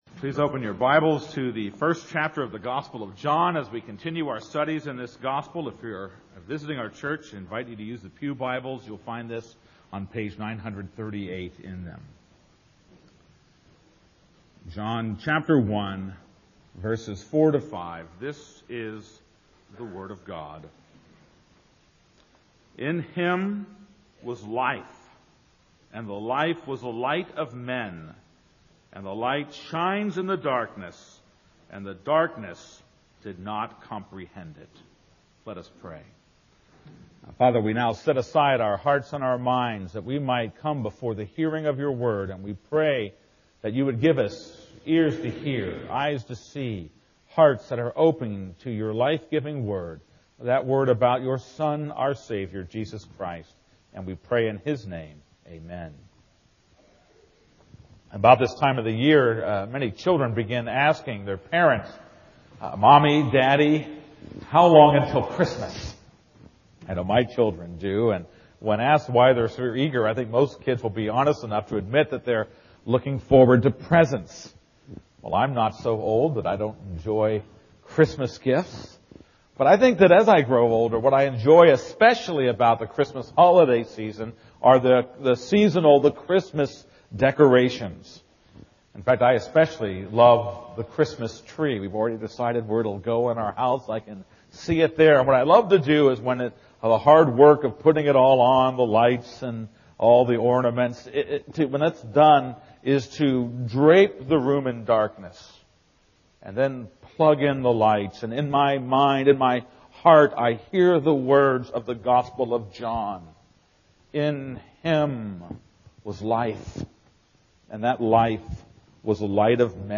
This is a sermon on John 1:4-5.